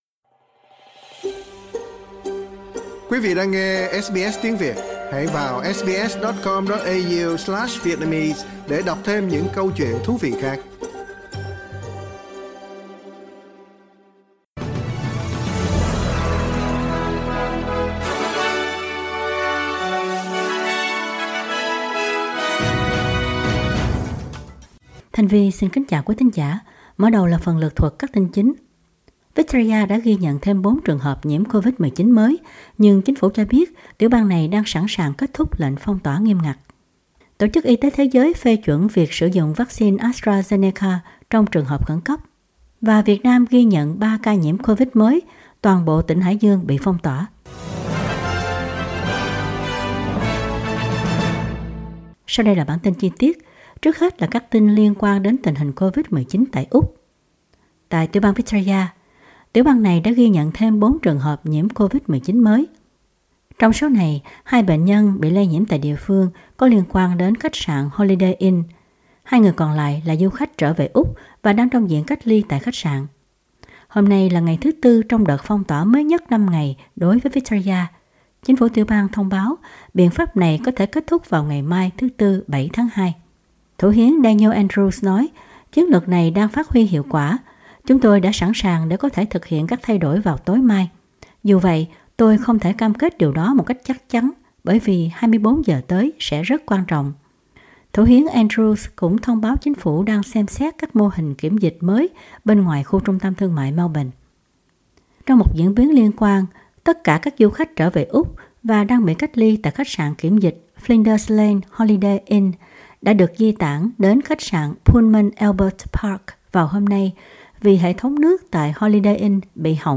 Bản tin chính trong ngày của SBS Radio.
Vietnamese news bulletin Source: Getty